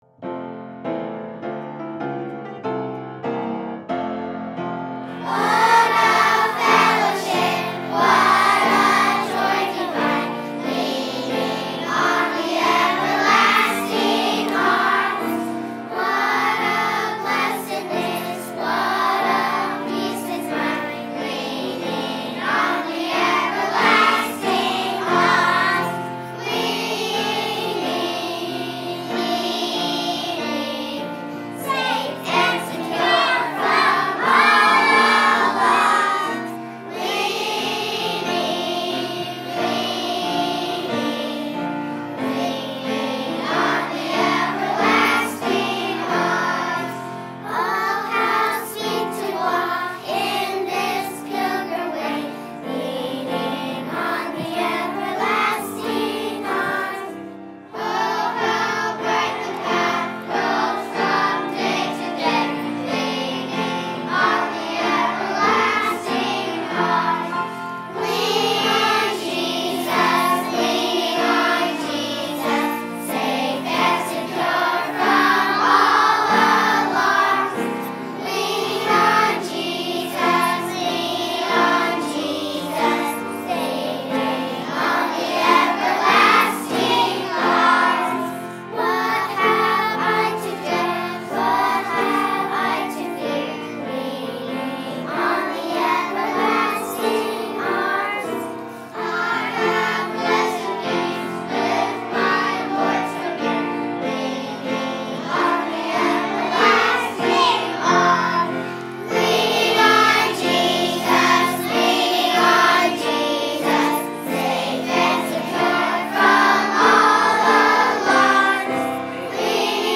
Leaning on the Everlasting Arms | VBC Children’s Choir (Mother’s Day 2023) | Banned But Not Bound
by VBC Children's Choir | Verity Baptist Church